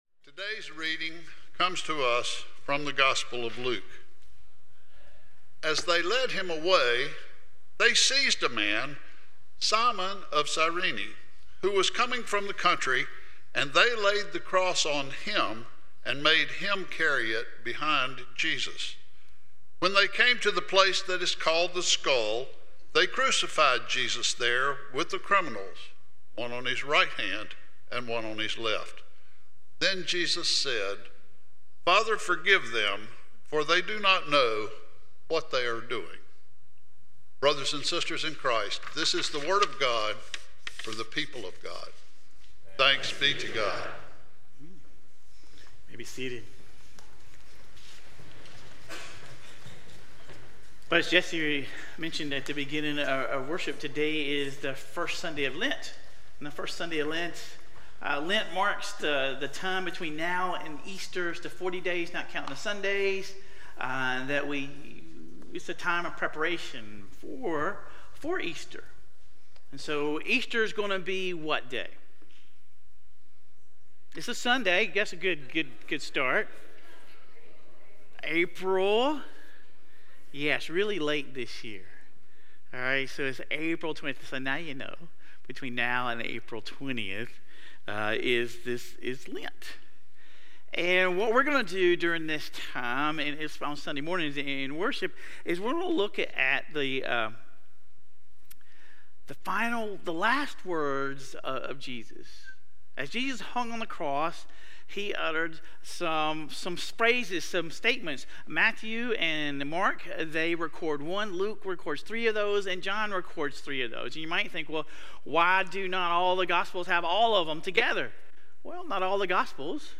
Sermon Reflections: In what ways have you experienced "missing the mark" recently, and how does Jesus's prayer for forgiveness from the cross speak to that struggle?